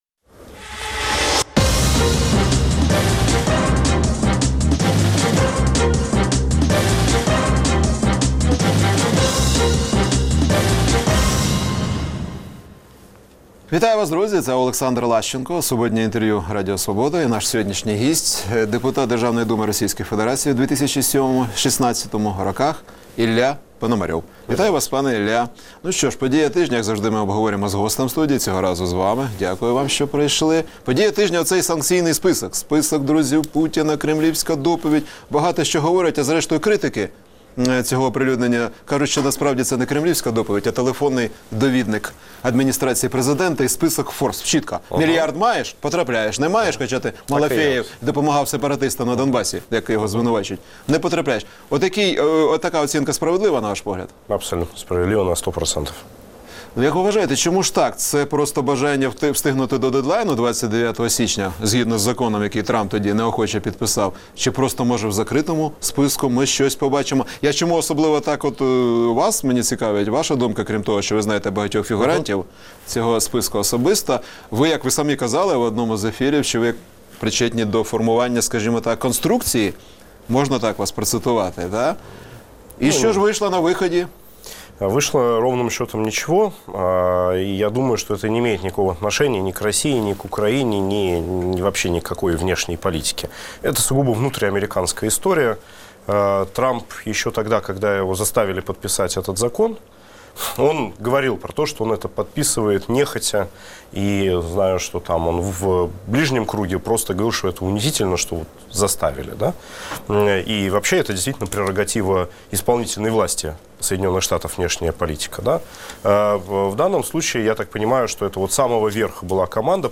Суботнє інтерв’ю | Ілля Пономарьов, російський політичний діяч
Суботнє інтвер’ю - розмова про актуальні проблеми тижня.